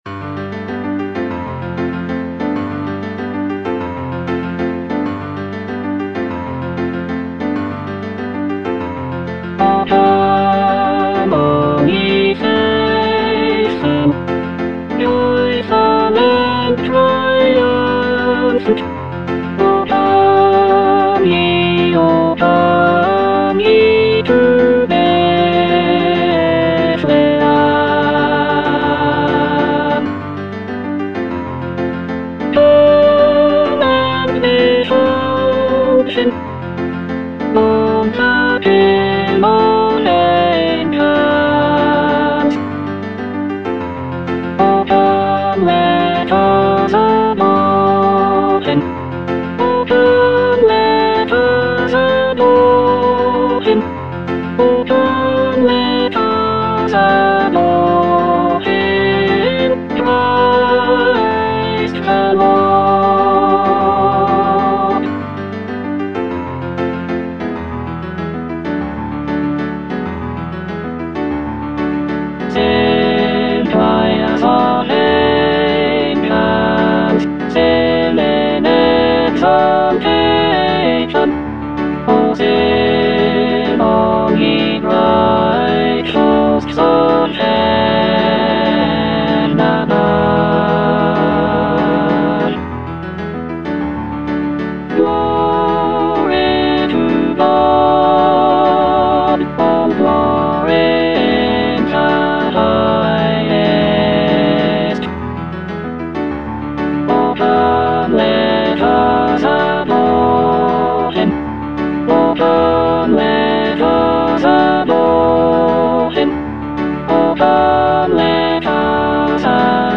Alto (Emphasised voice and other voices)